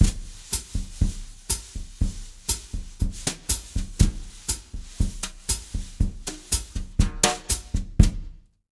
120BOSSA04-R.wav